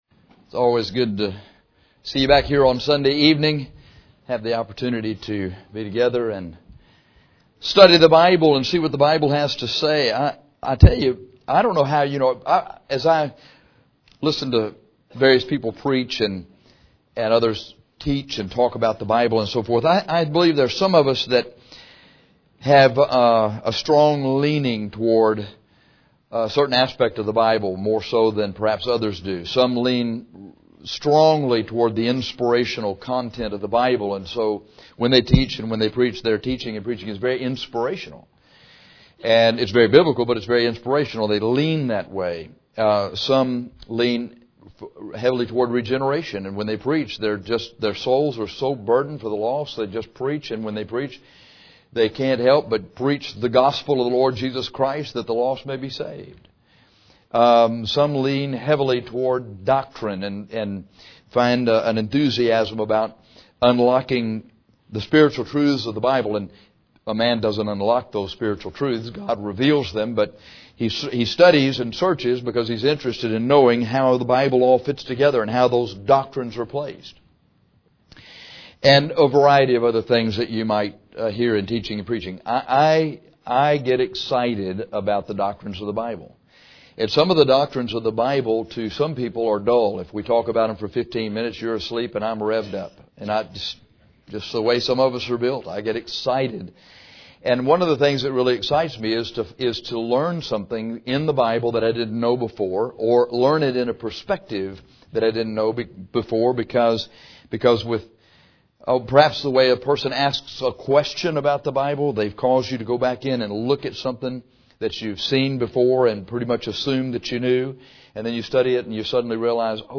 This lesson on baptized with the Holy Ghost is a doctrinal study on what this baptism is and what it does. In this study we will contrast this baptism with the baptism of 1 Cor 12:13.